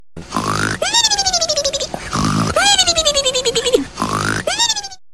Snore Meme Sound Effect Download: Instant Soundboard Button
The Snore Meme sound button is a popular audio clip perfect for your soundboard, content creation, and entertainment.